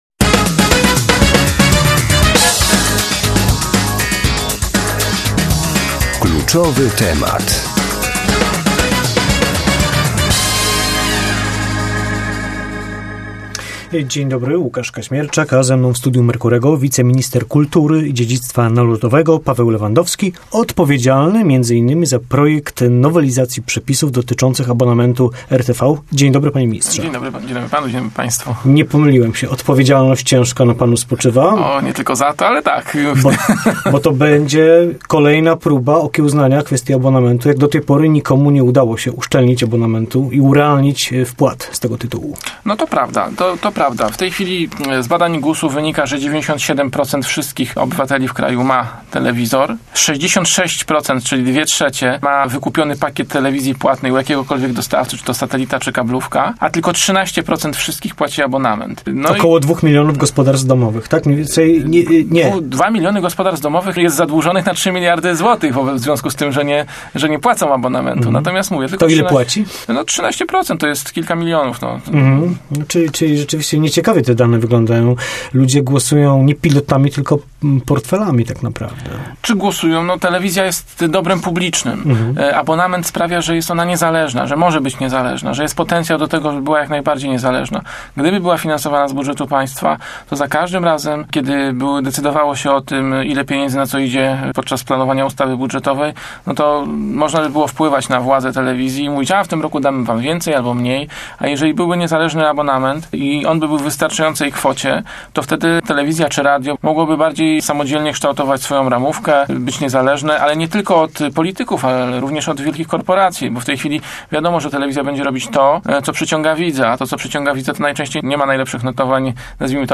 Prawdopodobnie w przyszłym tygodniu do Rady Ministrów trafi projekt nowelizacji ustawy o abonamencie radiowo-telewizyjnym – poinformował w rozmowie z Radiem Merkury Paweł Lewandowski, wiceminister Kultury i Dziedzictwa Narodowego.